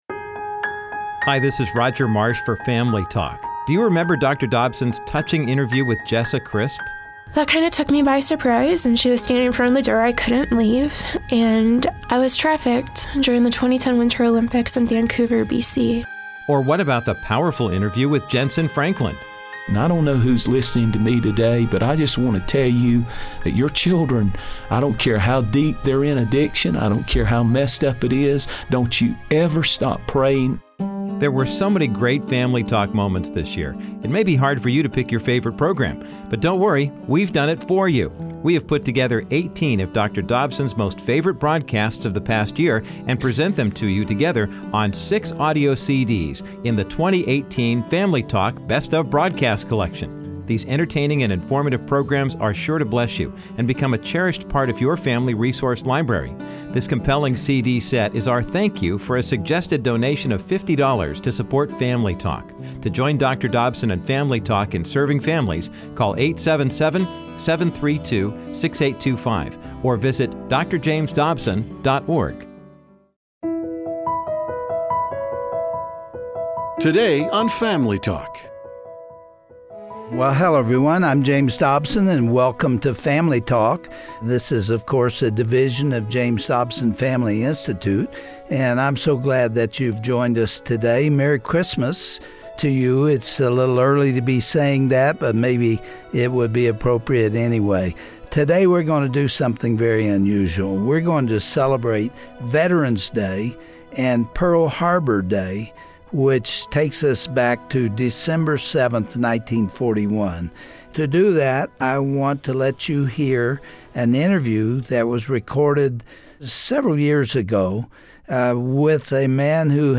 On this classic broadcast of Family Talk, were remembering one of the most dramatic moments in U.S history, the attack on Pearl Harbor. Today legendary singer and actor Pat Boone sits down with Dr. Dobson to reminisce about that dark day. Both men were young when the horrific attack occurred, but their memories help us honor the sacrifices made that day to preserve our freedom.